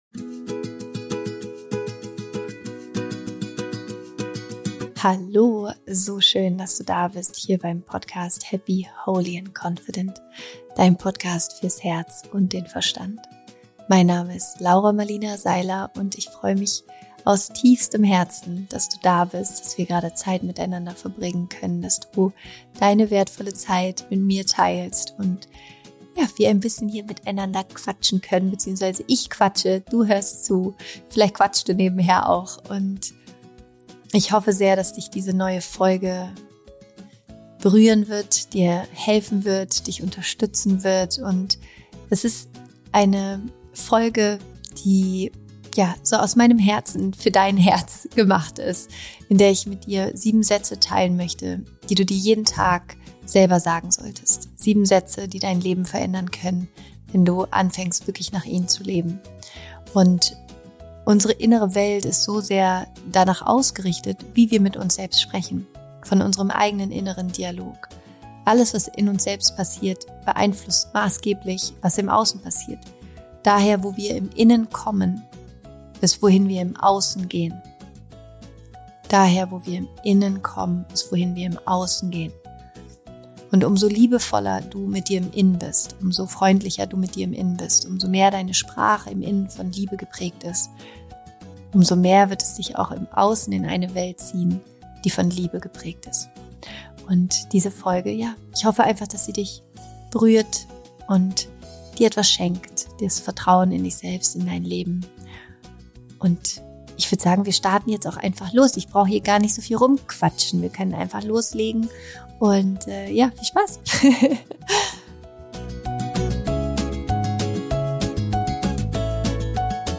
Zum Abschluss findest du noch eine kleine Meditation, um dich ganz mit den sieben Sätzen zu verbinden.